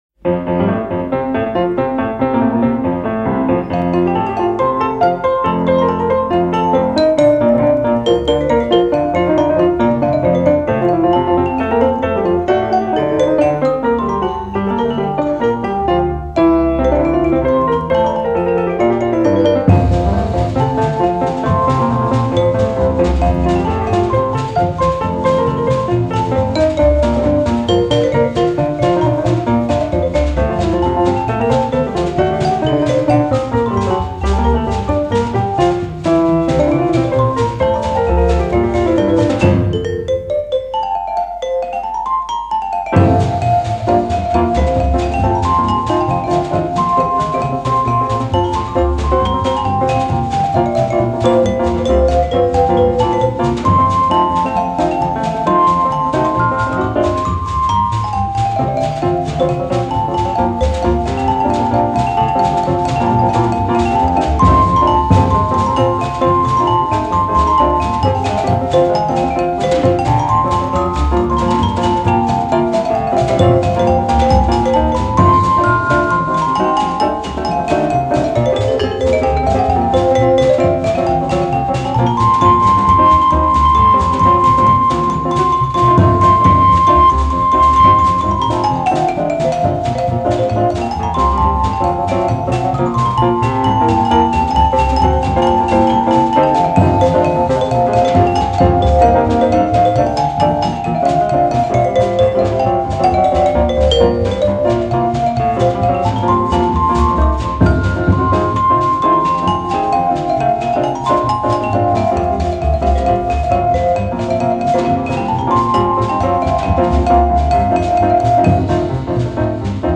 piano
vibraphone
bass
drums
studio nagra� PR w Poznaniu